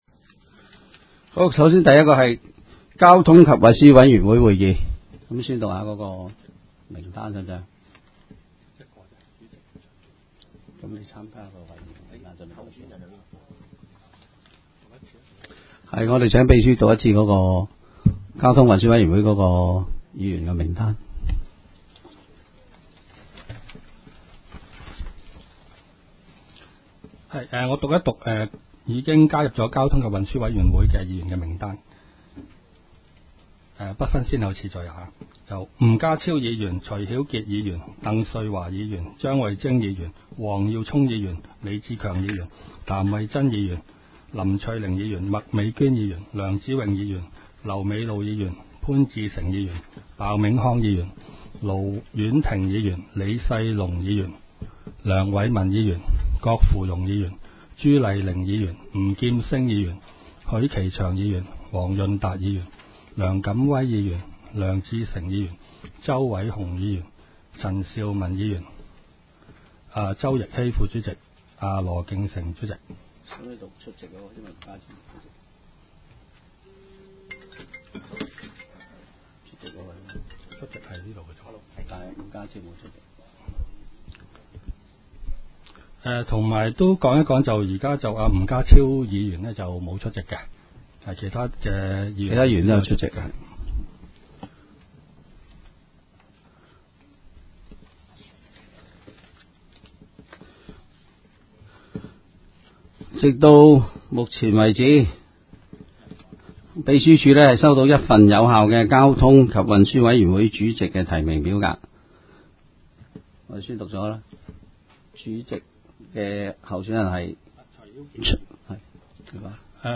委员会会议的录音记录
交通及运输委员会第一次特别会议会议 日期: 2016-01-19 (星期二) 时间: 下午2时47分 地点: 香港葵涌兴芳路166-174号 葵兴政府合署10楼 葵青民政事务处会议室 议程 讨论时间 开会词 00:07:33 1. 选举交通及运输委员会主席及副主席 00:35:02 全部展开 全部收回 议程: 开会词 讨论时间: 00:07:33 前一页 返回页首 议程:1.